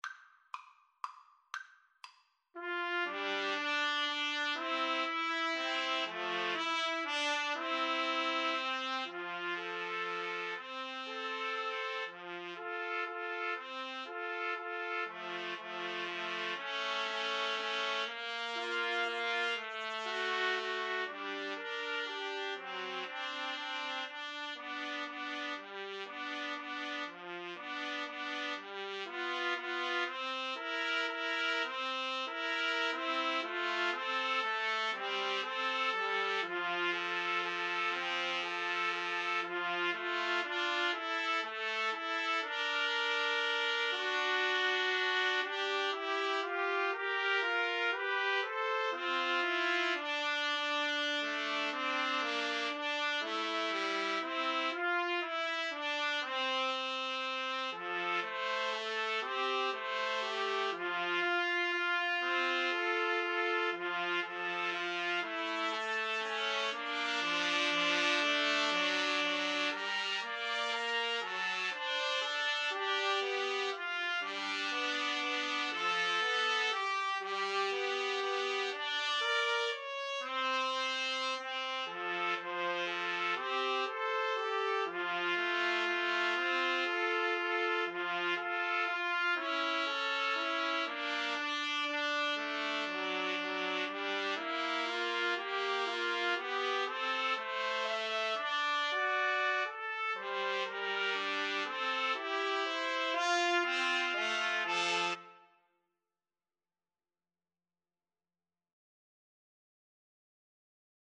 Trumpet 1Trumpet 2Trumpet 3
3/4 (View more 3/4 Music)
= 120 Tempo di Valse = c. 120